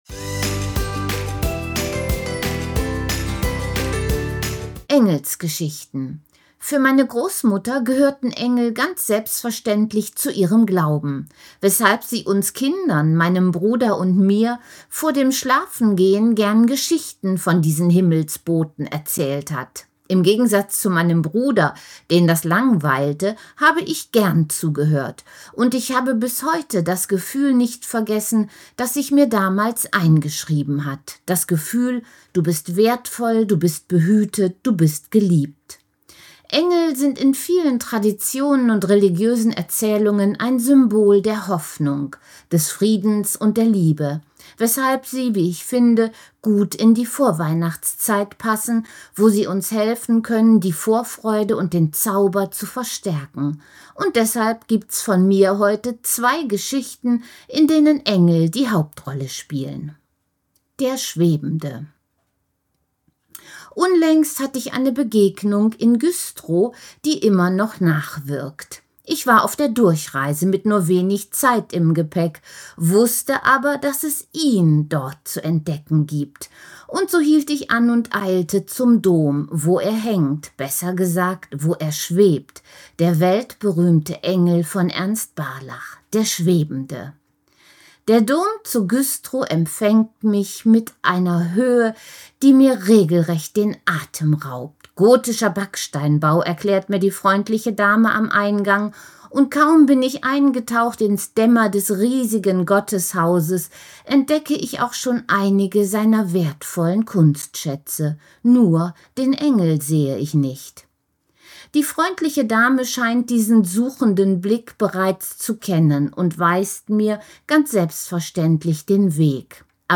Text als Audiodatei